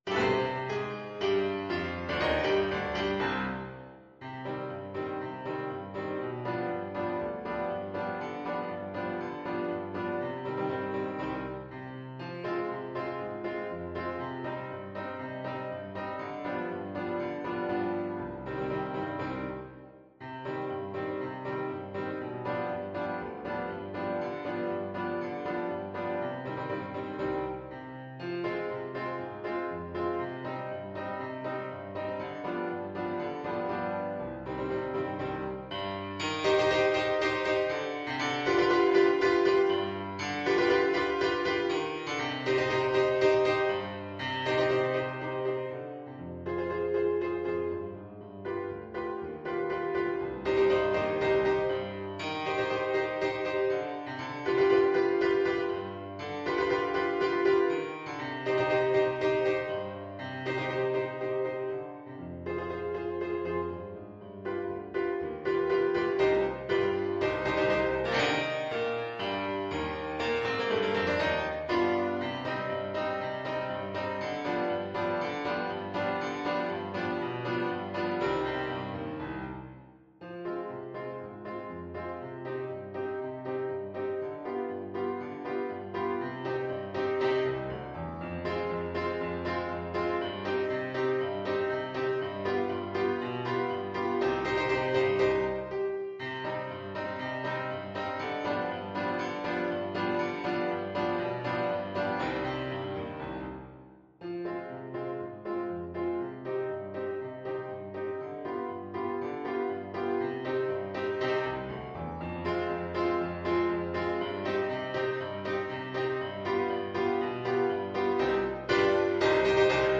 Allegro = c.120 (View more music marked Allegro)
Classical (View more Classical Trumpet Music)